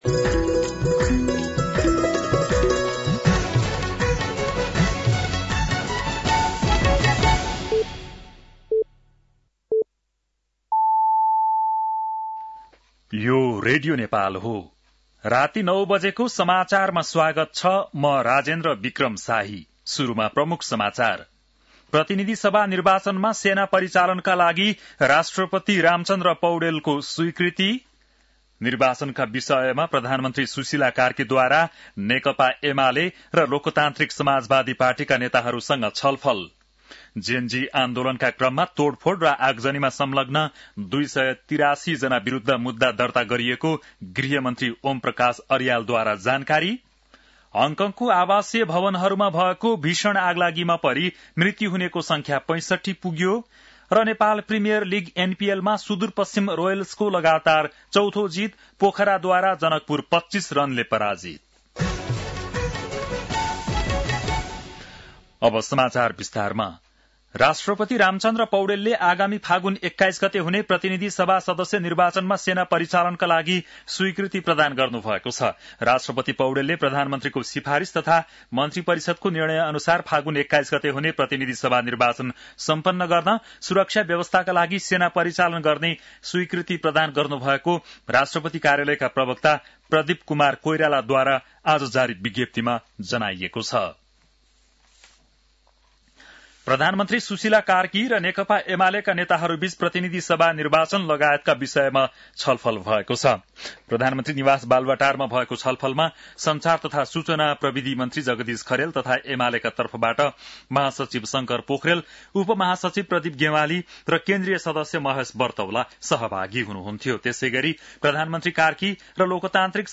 An online outlet of Nepal's national radio broadcaster
बेलुकी ९ बजेको नेपाली समाचार : ११ मंसिर , २०८२
9-PM-Nepali-NEWS-8-11.mp3